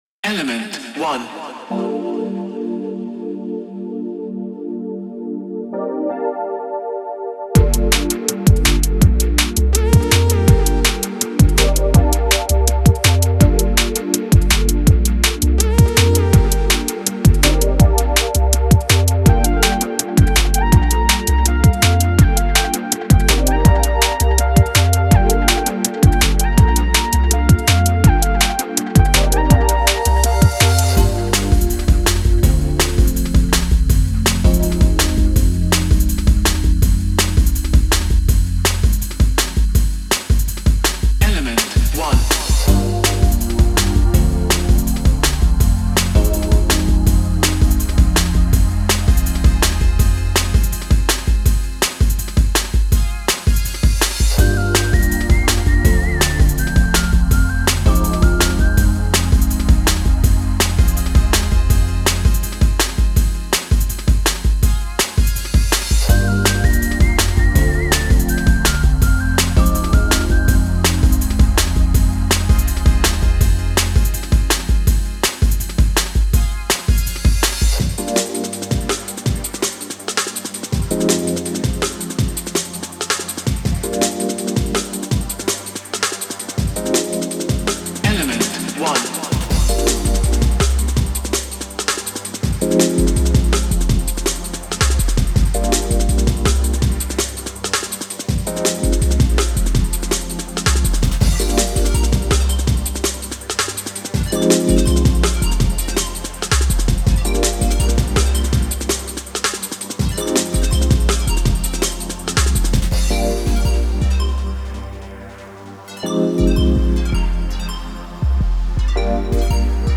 ジャングル系サンプルパックをご紹介いたします。